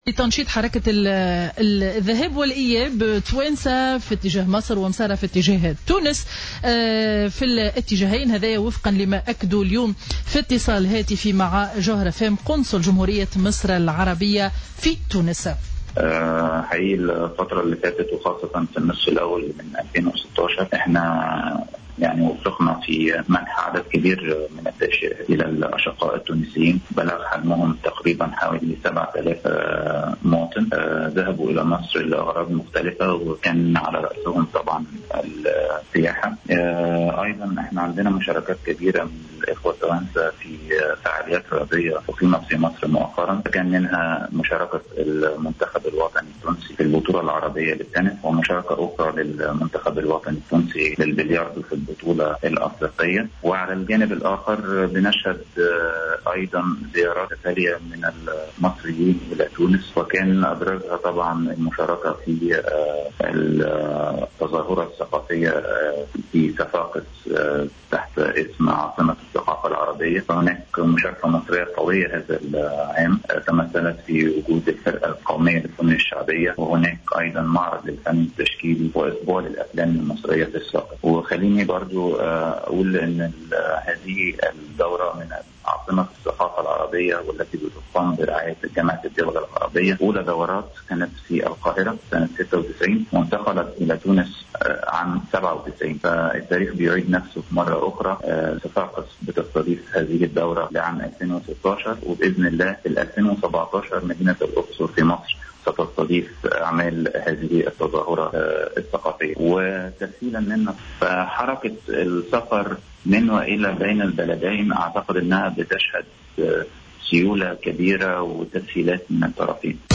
قال القنصل المصري في تونس،أحمد عزام في اتصال هاتفي مع "الجوهرة أف أم" إنه تم تسجيل حركة كبرى للمسافرين بين تونس ومصر خلال الفترة الماضية وذلك في ظل تسهيل إجراءات السفر في اتجاه البلدين.